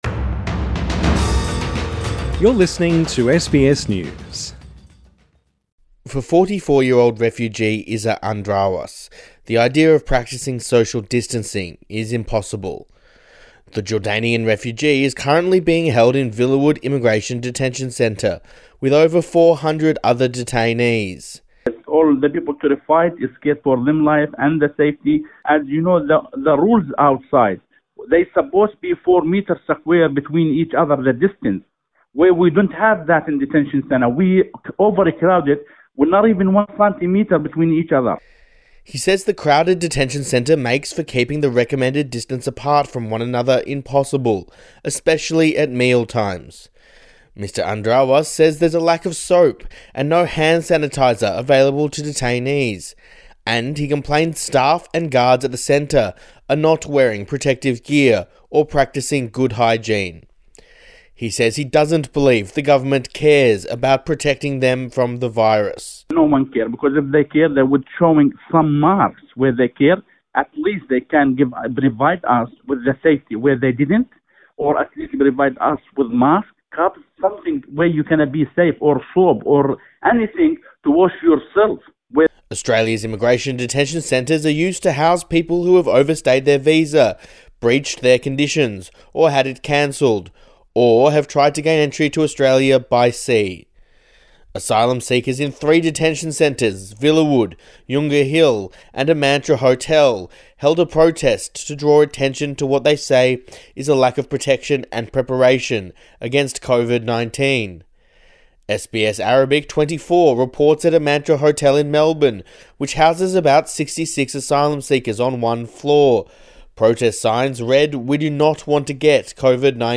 Two asylum seekers tell SBS News they - and others in immigration detention - are fearful COVID-19 may break out among them.